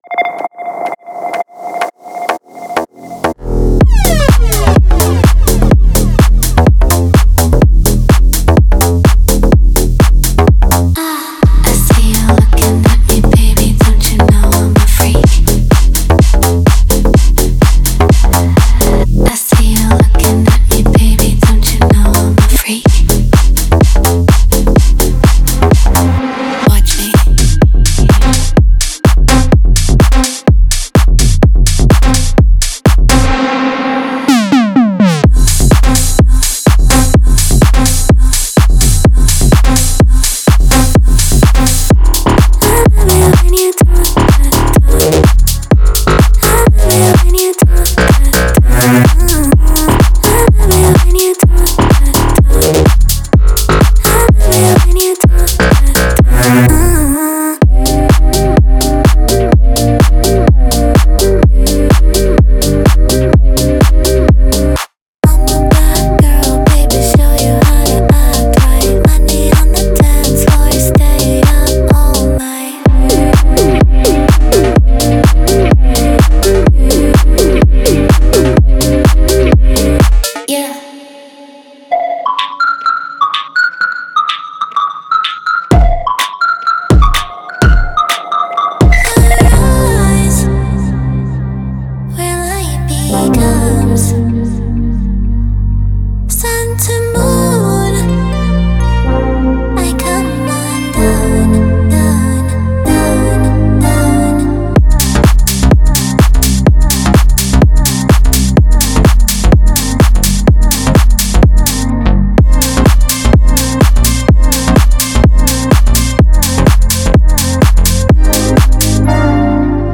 Genre:Pop
ハイエナジーなハウスと、ダークでアティチュード主導のポップの鋭い側面を融合させた内容です。
デモサウンドはコチラ↓
12 Vocal Hooks (Wet & Dry)